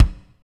Index of /90_sSampleCDs/Northstar - Drumscapes Roland/DRM_Medium Rock/KIK_M_R Kicks x